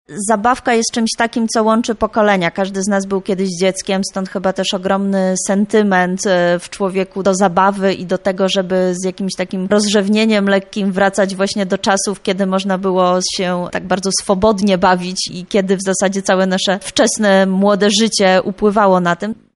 mówi z uśmiechem